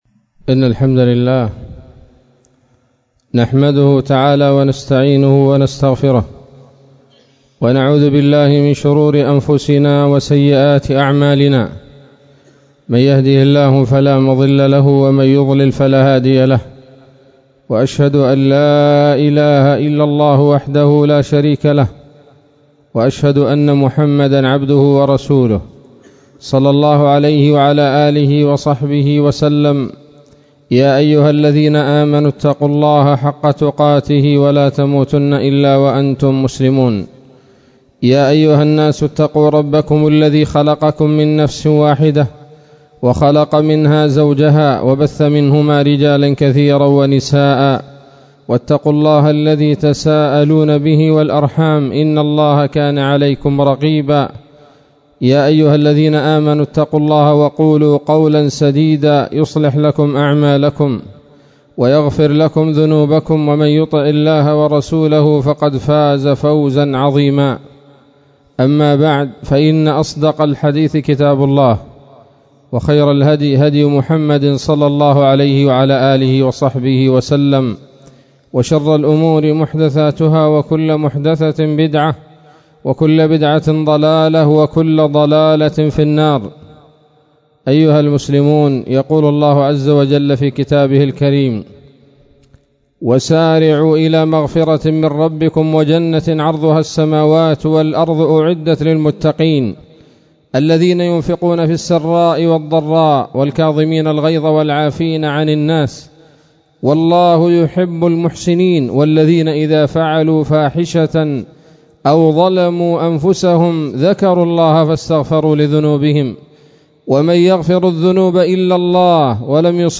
خطبة جمعة بعنوان: (( ثمار الاستغفار )) 28 من شهر رجب 1442 هـ